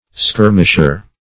Skirmisher \Skir"mish*er\, n.